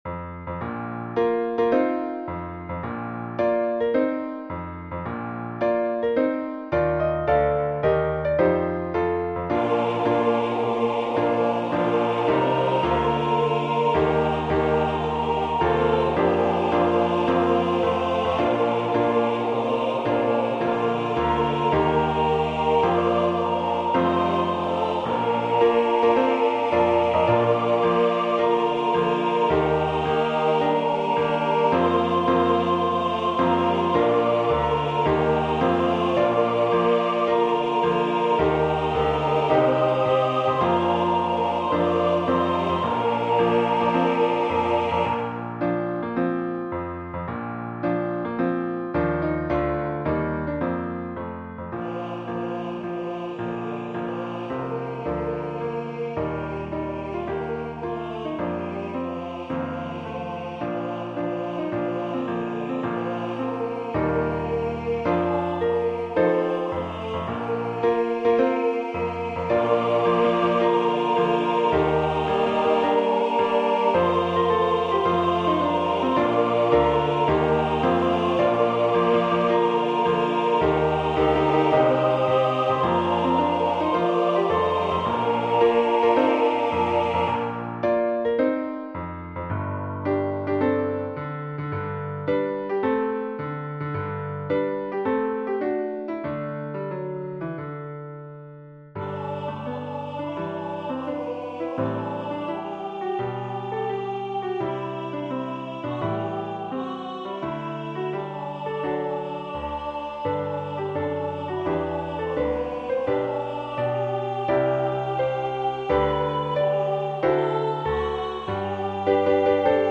SATB
Standing on the Promises Hymn #1023 STAB with Piano Accompaniment.
Voicing/Instrumentation: SATB
Includes Vocal Obbligato/Descant